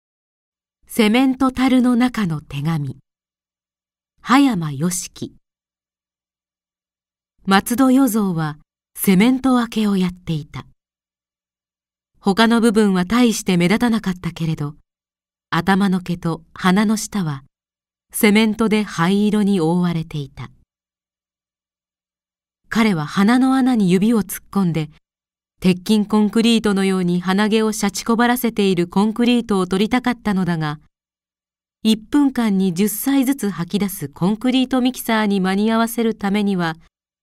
• 弊社録音スタジオ
朗読ＣＤ　朗読街道１２５「セメント樽の中の手紙・淫賣婦」葉山嘉樹
朗読街道は作品の価値を損なうことなくノーカットで朗読しています。